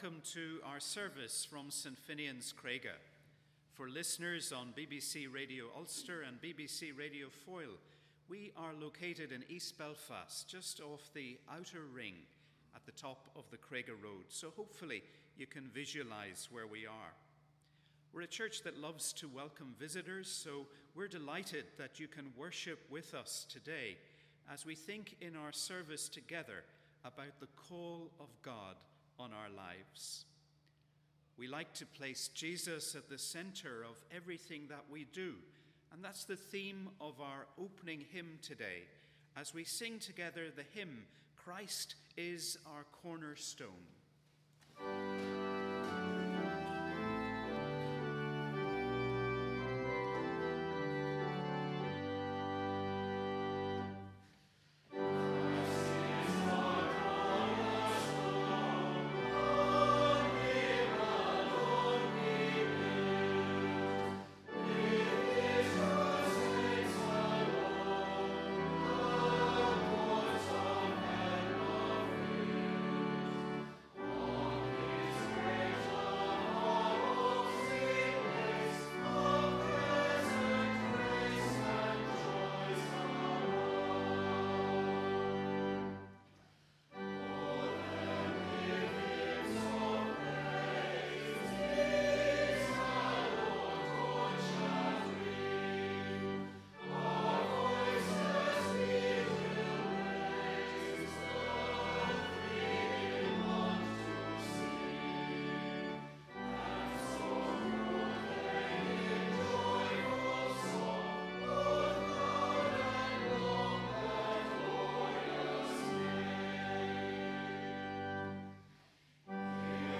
We warmly welcome to our service of Morning Prayer which, today, is being broadcast on BBC Radio Ulster.